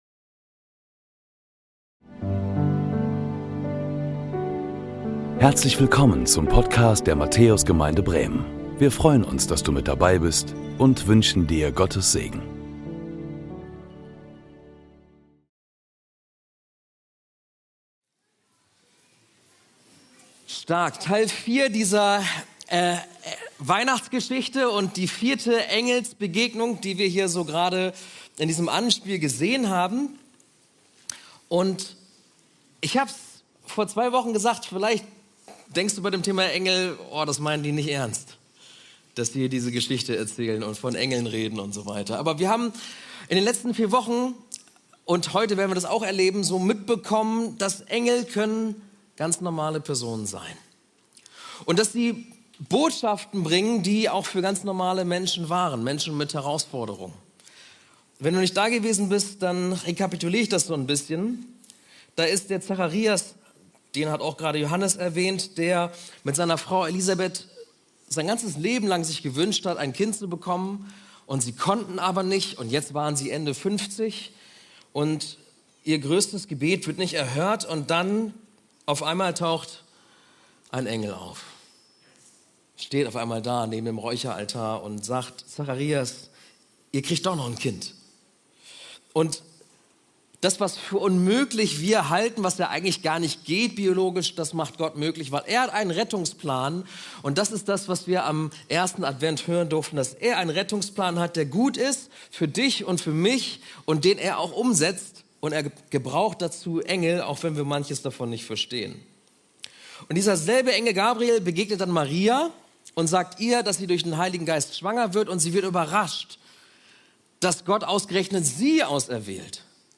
Herzlich Willkommen zu unserem 2. Gottesdienst um 12 Uhr am 4. Advent.